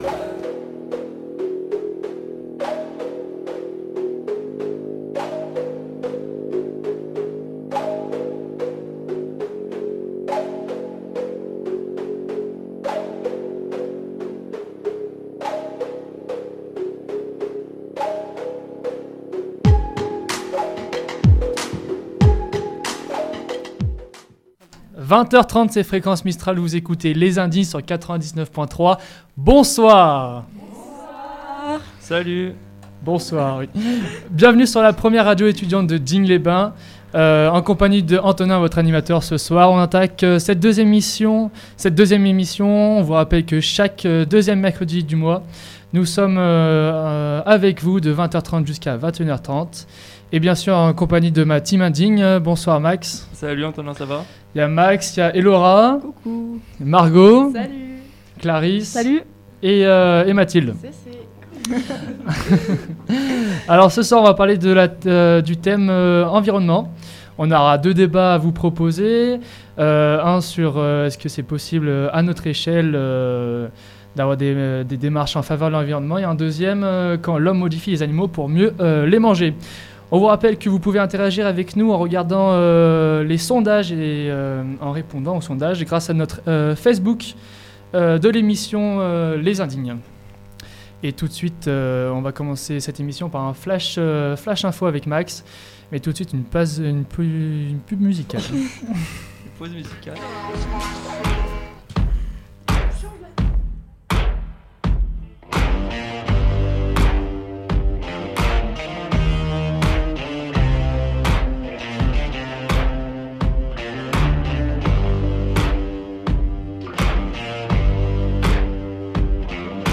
2ère émission en direct sur Fréquence Mistral Digne 99.3 avec les étudiants en Génie Biologique 1ère année